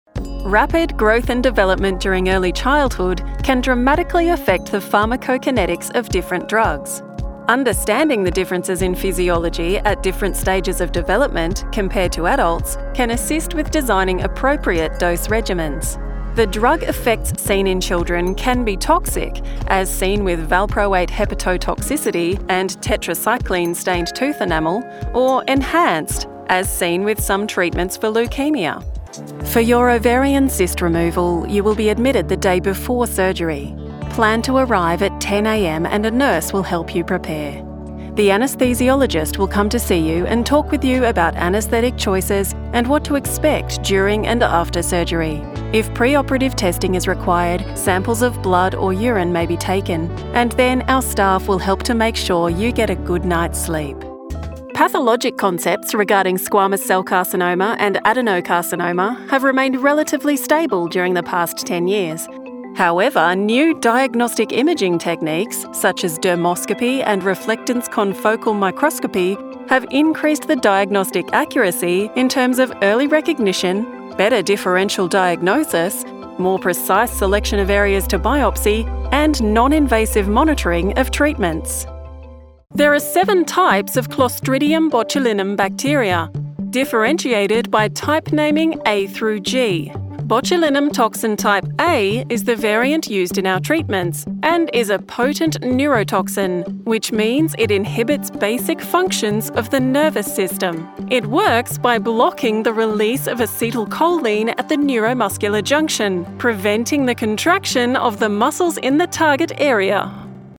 Female
English (Australian)
Natural, relatable Australian voiceover for impactful commercials, understandable medical content, warm corporate video and engaging audiobooks.
Medical Narration Demo
Words that describe my voice are Medical narration expert, Warm and comfortable, Authentic Australian.